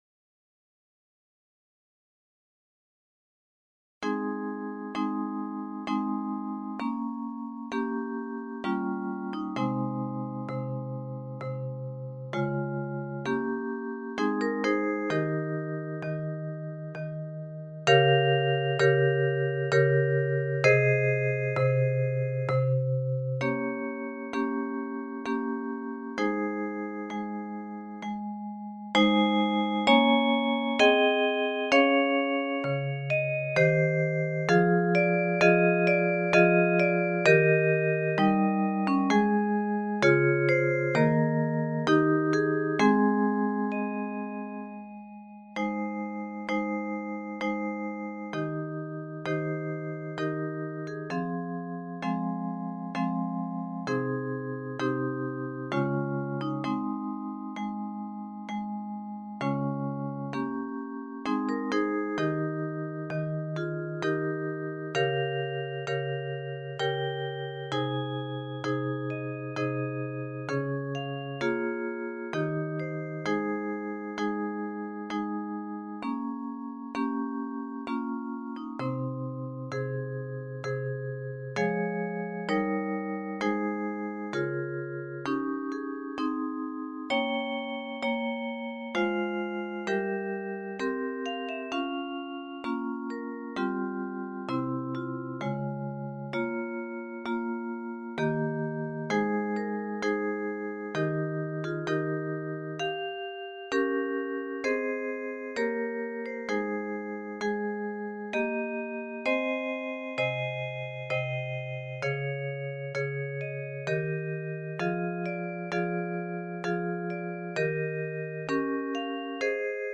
Arranged for 3 octaves of handbells